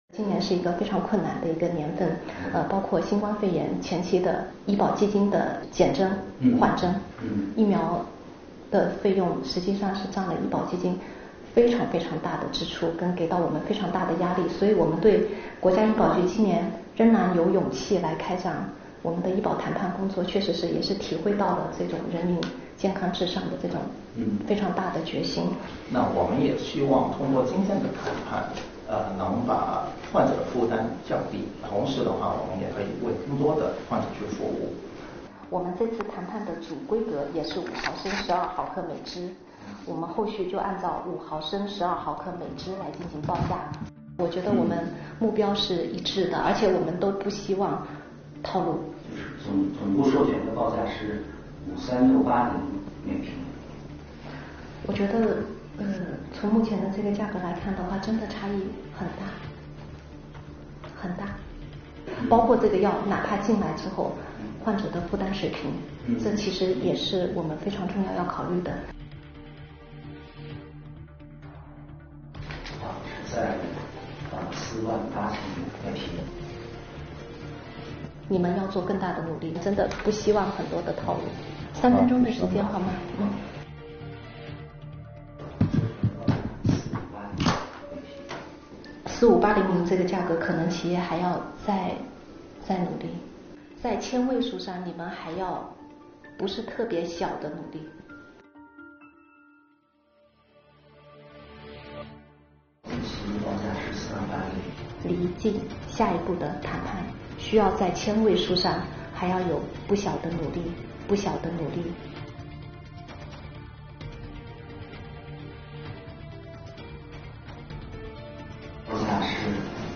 2021国家医保目录药品谈判现场